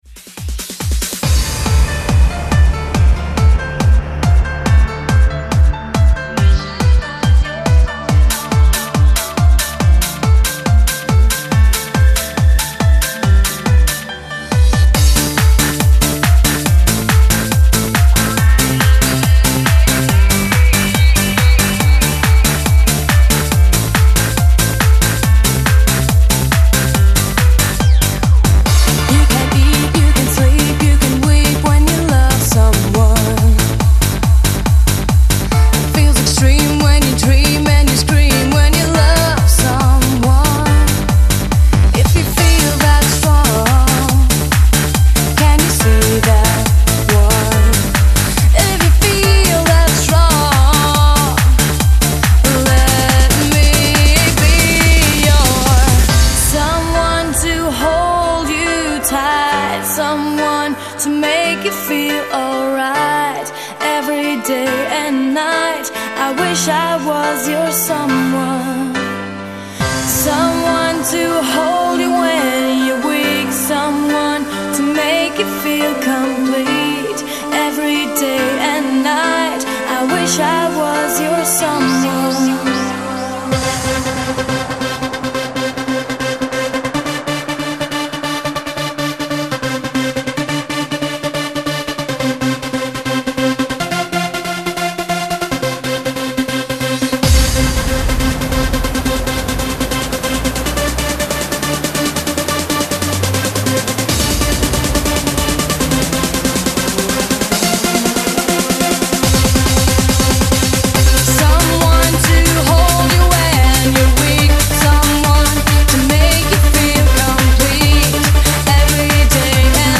BPM70-140
Audio QualityPerfect (High Quality)
CommentsFile notes: There is a slowdown about halfway.